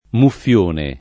muffione [ muff L1 ne ]